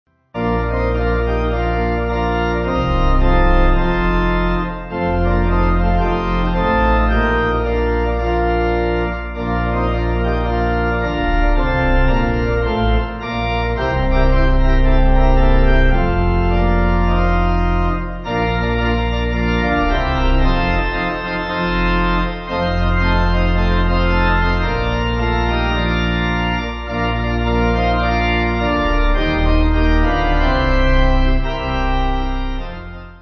Organ
(CM)   4/Bb